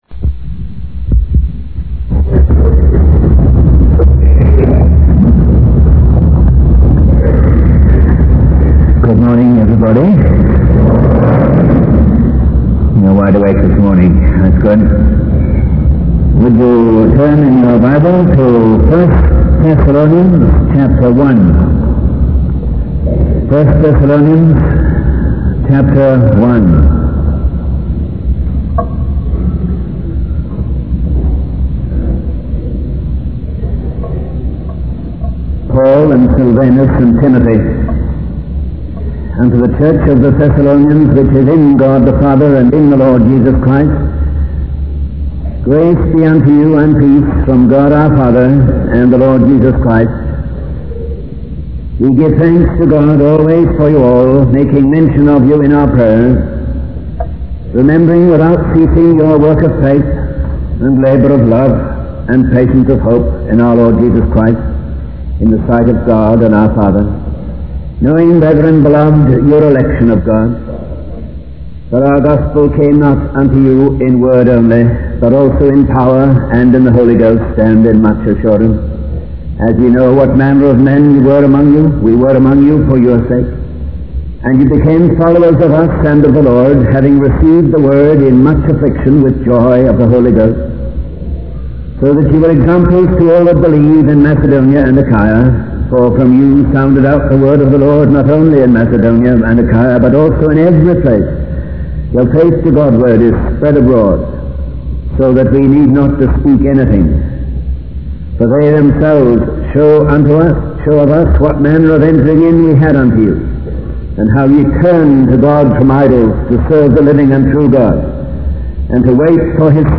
In this sermon, the speaker begins by describing a group of people who are wounded and in need of help.